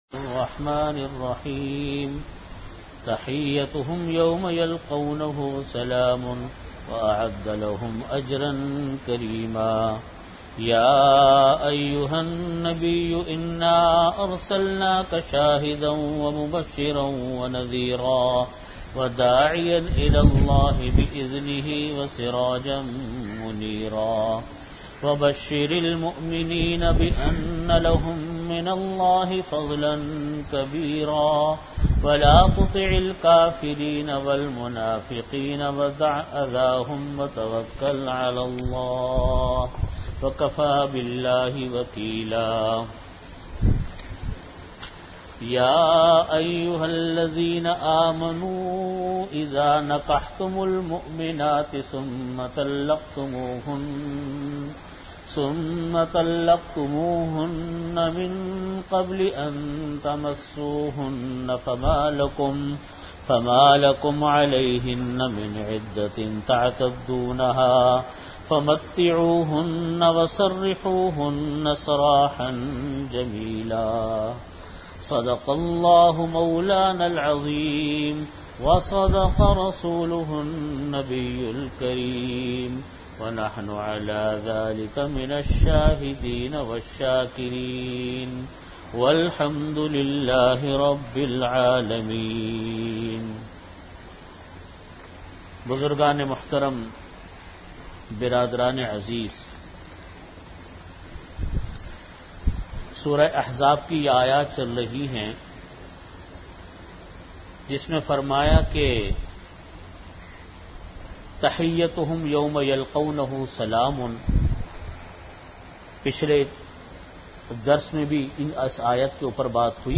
Audio Category: Tafseer
Time: After Magrib Prayer Venue: Jamia Masjid Bait-ul-Mukkaram, Karachi